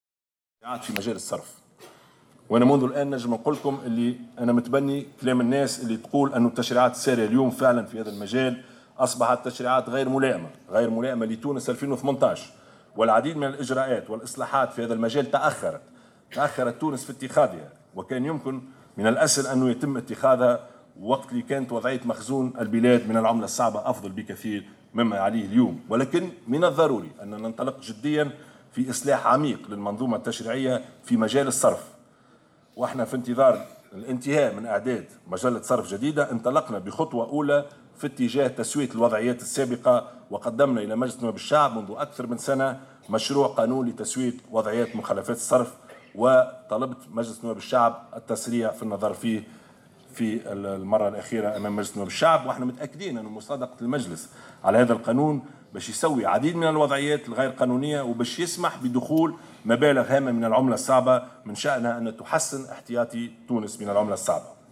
وأضاف في كلمة ألقاها اليوم خلال افتتاحه فعاليات أيام المؤسسة بسوسة، أنه من الضروري الانطلاق جديا في اصلاح عميق للمنظومة التشريعية في مجال الصرف، مشيرا إلى أنه سيتم الانتهاء من إعداد مجلة صرف جديدة، مؤكدا أيضا أنه طلب من مجلس نواب الشعب تسريع النظر في مشروع قانون لتسوية مخالفات الصرف، والذي سيمكن من تسوية عديد الوضعيات غير القانونية ومن دخول مبالغ هامة من العملة الصعبة من شأنها أن تحسن احتياطي تونس.